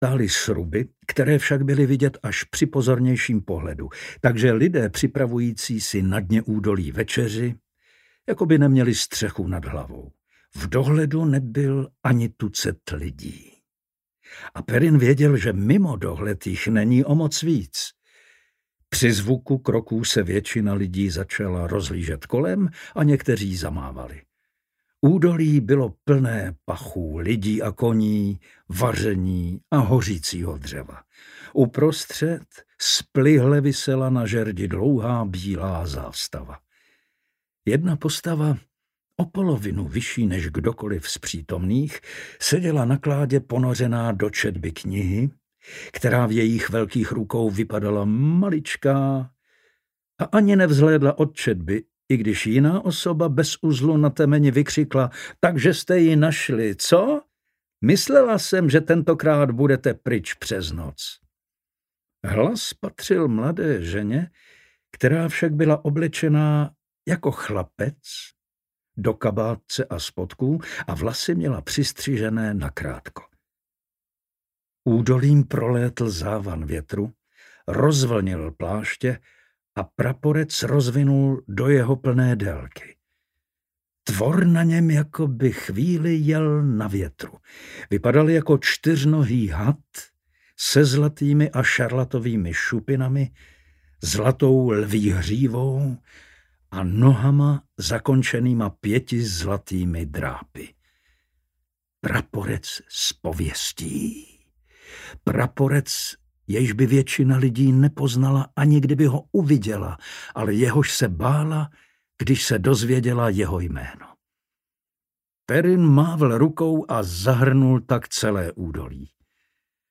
Drak Znovuzrozený audiokniha
Ukázka z knihy
• InterpretPavel Soukup